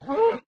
cow
hurt2.ogg